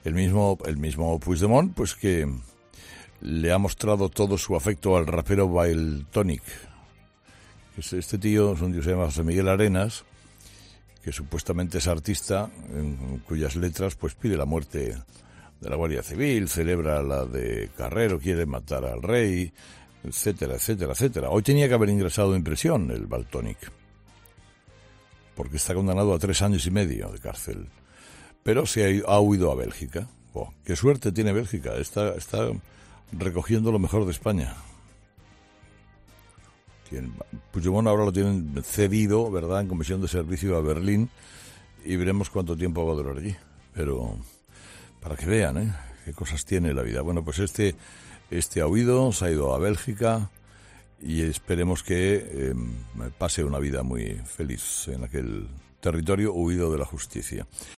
Carlos Herrera ha aprovechado su monólogo de este jueves para comentar la huida del rapero Valtonyc tras ser condenado a tres años y medio de prisión.
"Este tío se llama José Miguel Arenas y spuestamente es artista. En sus letras... pues pide matar a un Guardia Civil, celebra la de Carrero, quiere matar al Rey, etcétera, etcétera, etcétera. Hoy tenía que haber ingresado en prisión porque está condenado a tres años y medio de cárcel, pero se ha ido, ha huido a Bélgica. ¡Qué suerte tiene Bélgica, está recogiendo lo mejor de España!", ha dicho con ironía Herrera.